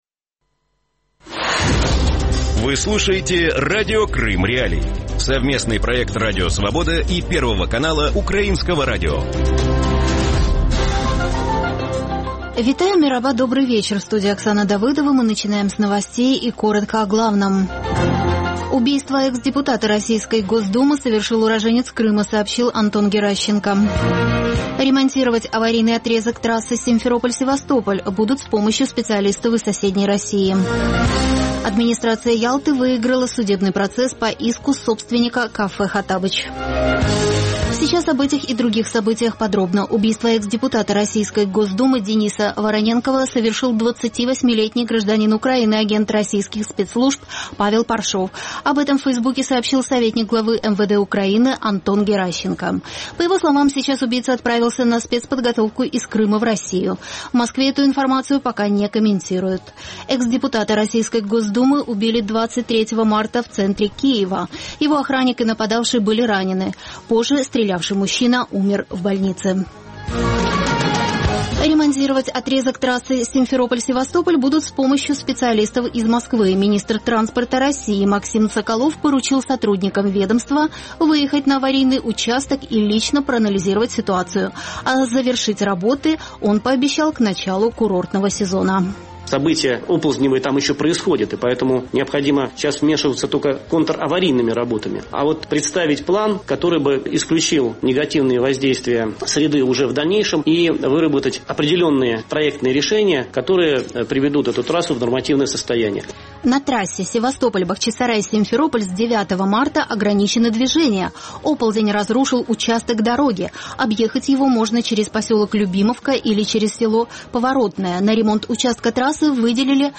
Новости Радио Крым.Реалии